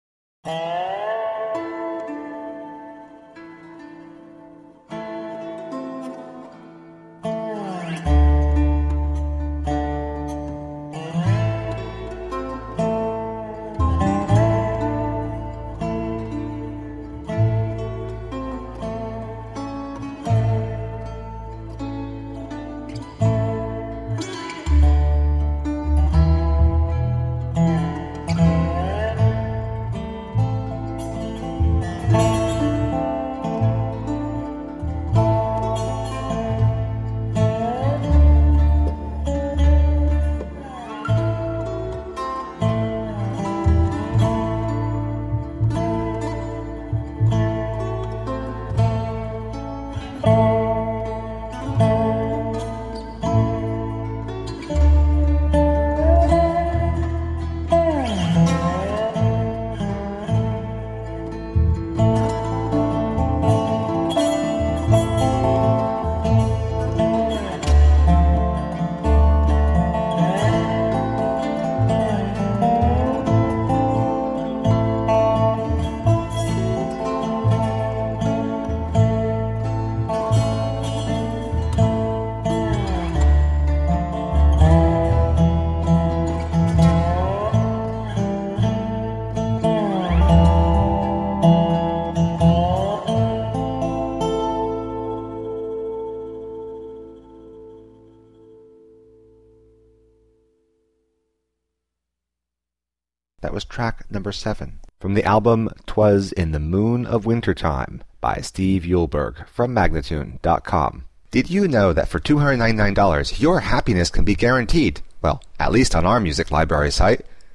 Smile-inducing, toe-tapping folkgrass.